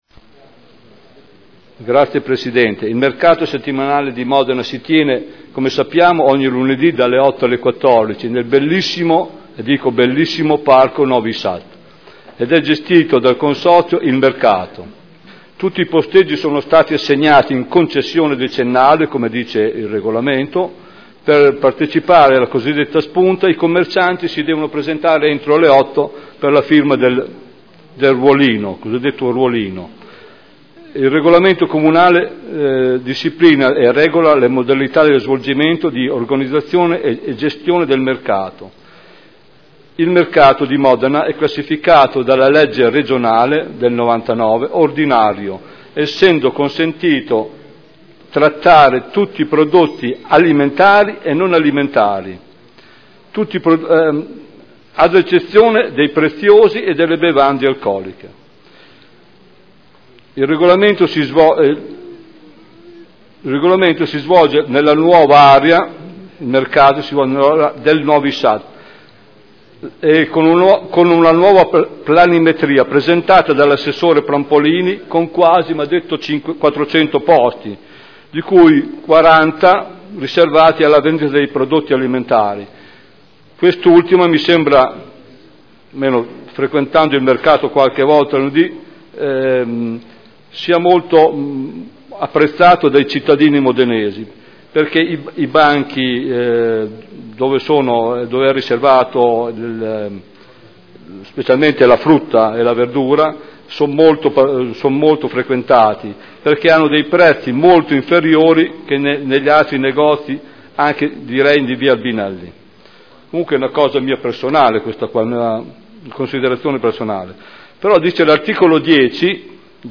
Stefano Goldoni — Sito Audio Consiglio Comunale
Seduta del 12/12/2013 Proposta di deliberazione: Mercato settimanale del lunedì – Variazione posteggi . Dibattito